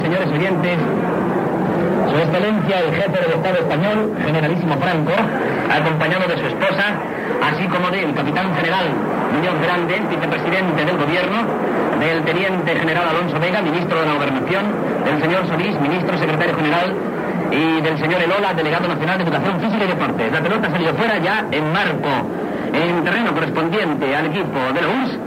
Inici de la transmissió amb el nom de les autoritats presents al partit Espanya-Rússia de la Copa d'Europa de Nacions de futbol masculí, jugat a l'estadi Santiago Bernabeu.
Esportiu